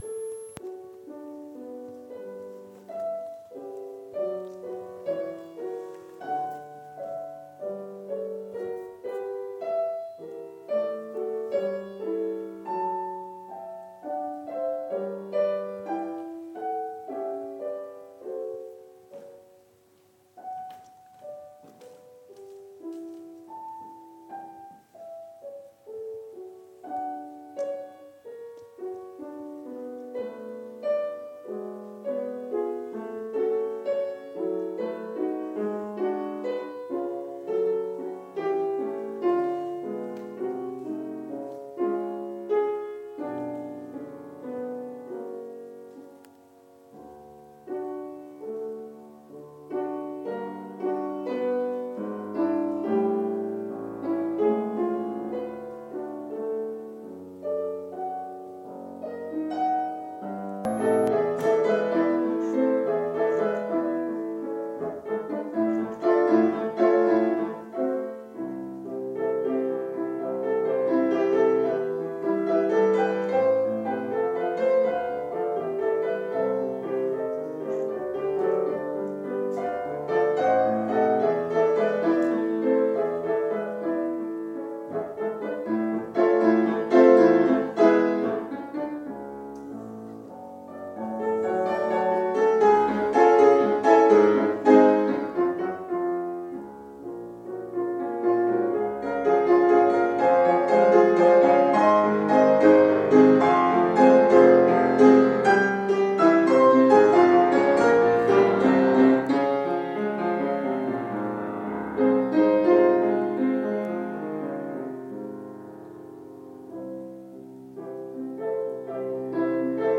kaisersaal klavierkonzert 1